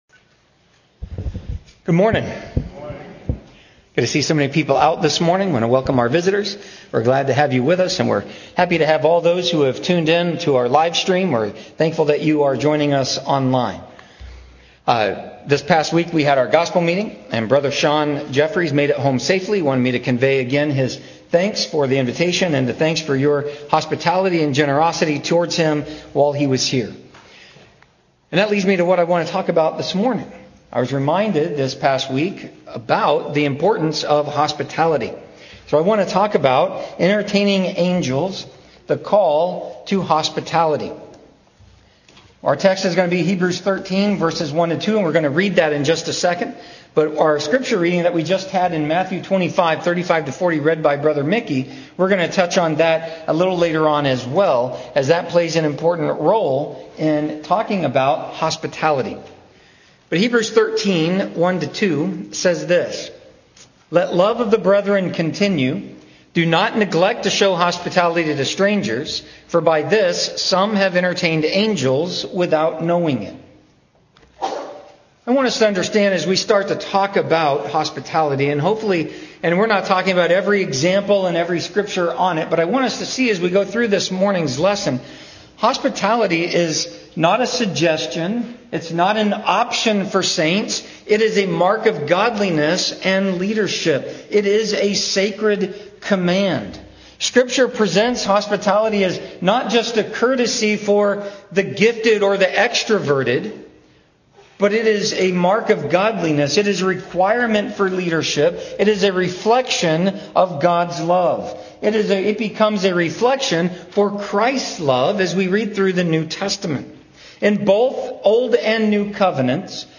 Entertaining_Angels_The_Call_to_Hospitality_MP3_Mono.mp3